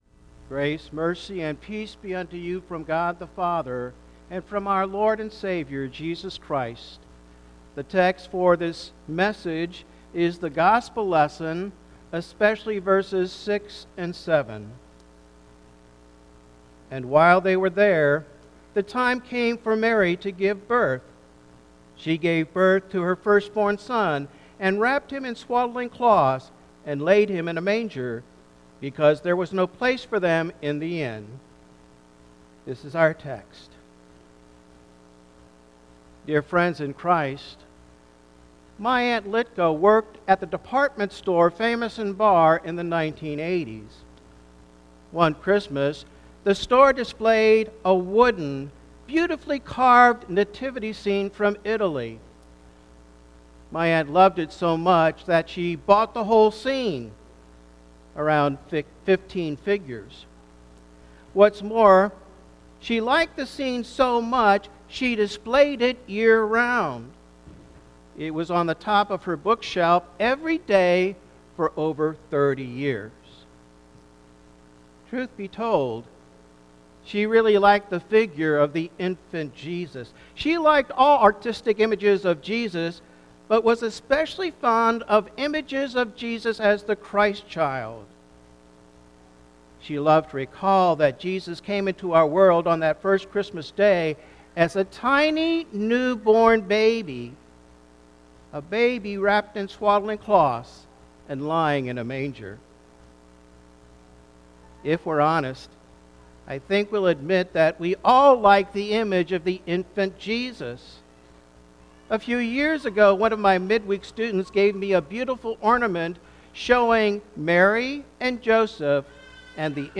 - Blessed Savior Lutheran Church